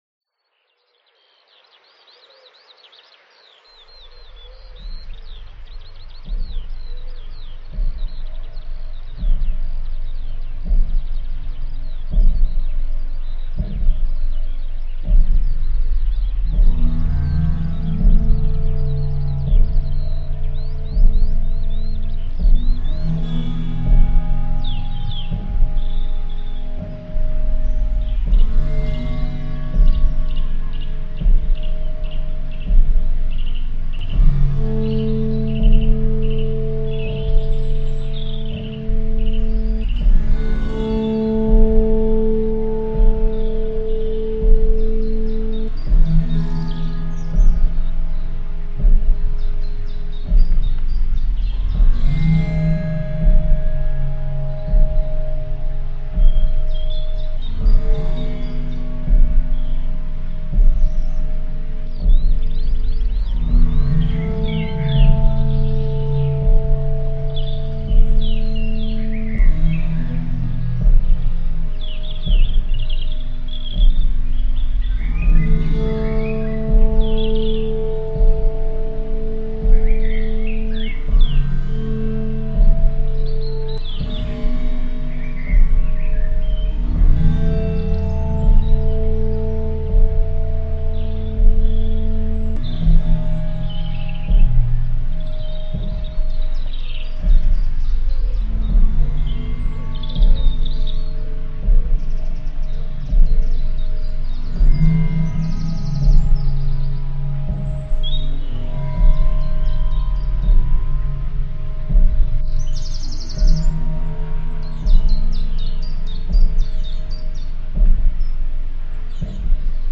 Out to greet the frost and the dawn, a wander through the woods to the west of Banbury finds a gentle dawn chorus that signals the arrival of Spring.
As I plod along I feel the deep slow beat of a shaman’s drum conjured from my boots clomping down on the frozen ground.
Lately I’ve been experimenting with the autoharp I was recently gifted. The scene makes me think of slow, Bagpuss-esque rising scales with heavy reverb.
Descending into the woods I am greeted by a soundscape of birdsong.
Blackbirds, robins, pigeon, sparrow, and blue and great tits all combine to create an ethereal soundtrack to complement the misty dawn (this recording was made using the camera’s condenser microphone, so it doesn’t really do justice the depth of the melodic scene).